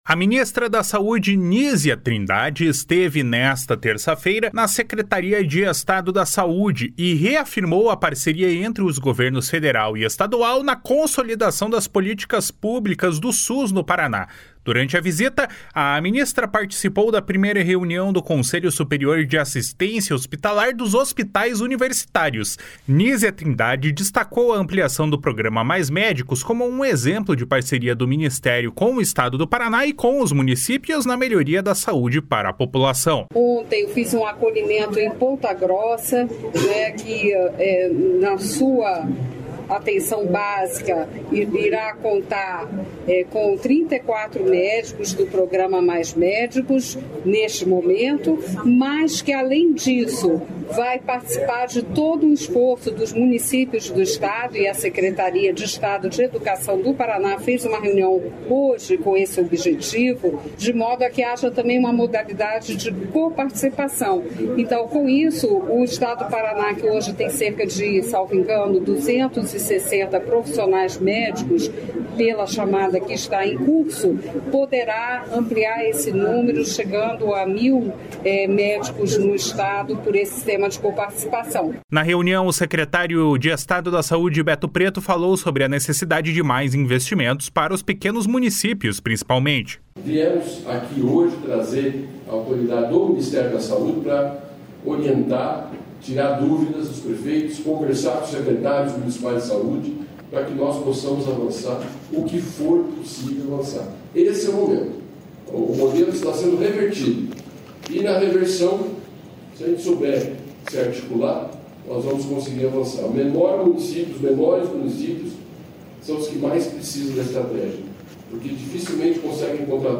Nísia Trindade destacou a ampliação do programa Mais Médicos, como um exemplo de parceria do Ministério com o Estado do Paraná e os municípios na melhoria da saúde para a população. // SONORA NÍSIA TRINDADE //